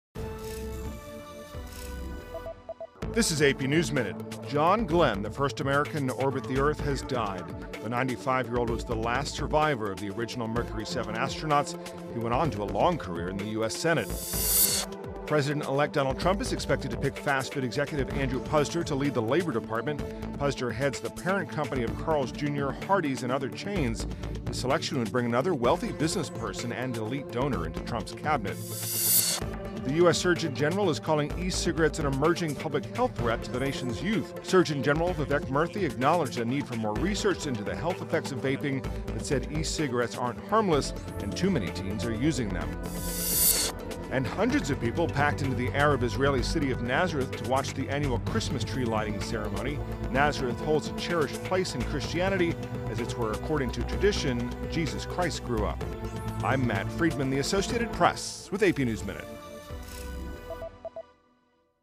美语听力练习素材:美国第一位绕地球飞行的宇航员去世|美语听力练习素材
News